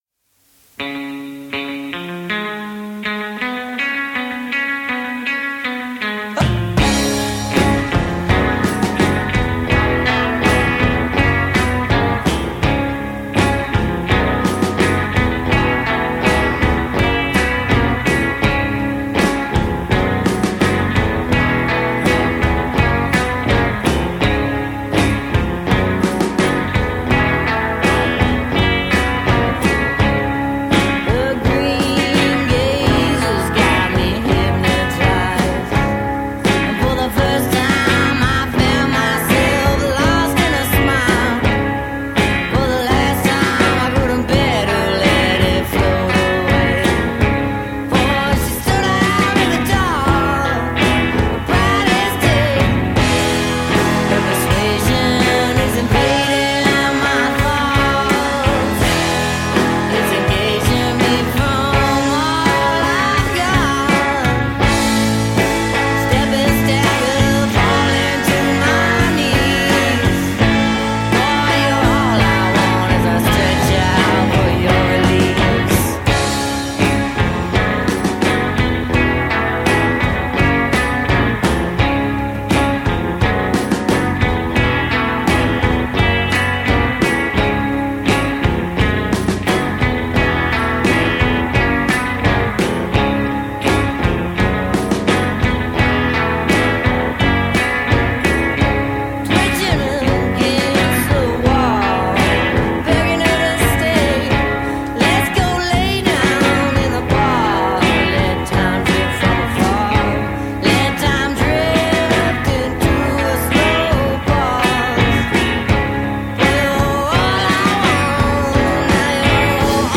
Loose, jangly riffs, bluesy drawls…probs a Geelong band.